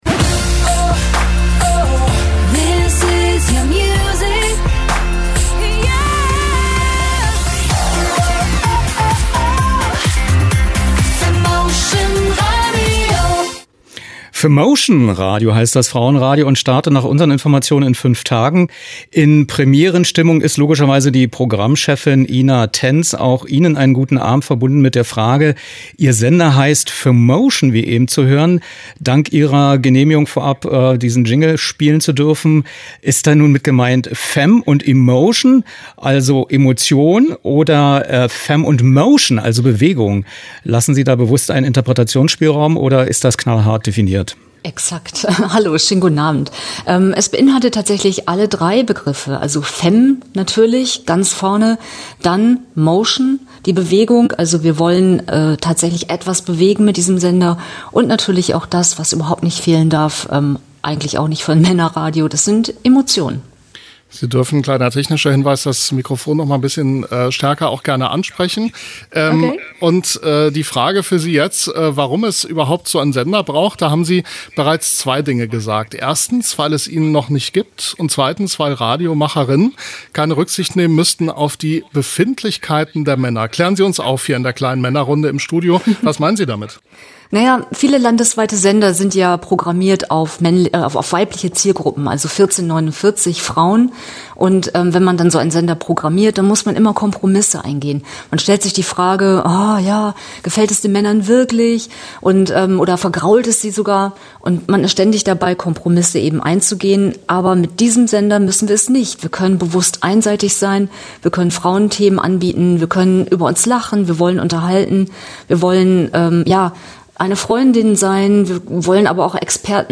Die Meldungstexte wurden vorher aufgezeichnet und die Audios vermutlich nach dem Abwechslungs-Prinzip in jeder Stunde in einer anderer Reihenfolge vom Computer abgefahren (Voicetracking).
Was: Wortanteil der ersten offiziellen Stunde mit Begrüßung der Hörerinnen
Nachrichtenredakteurin, -präsentatorin
Moderatorin